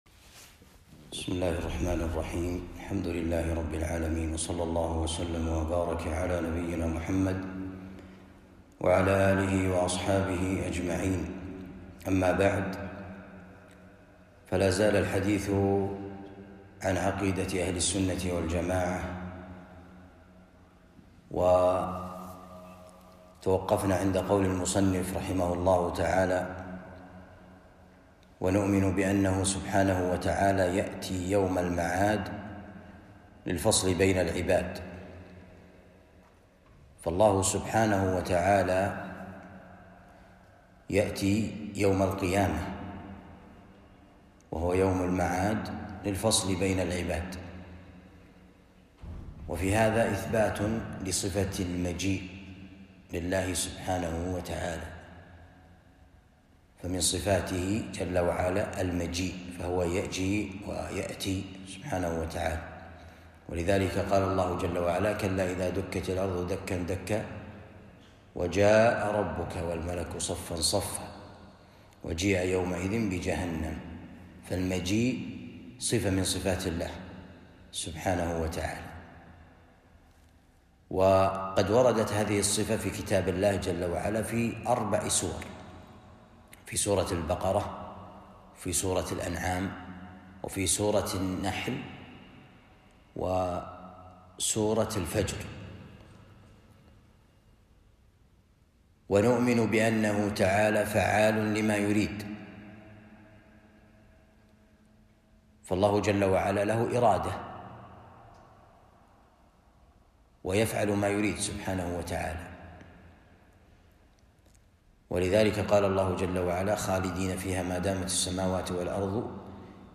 التعليق على كتاب عقيدة أهل السنة والجماعة للشيخ ابن عثيمين رحمه الله ((الدرس الرابع))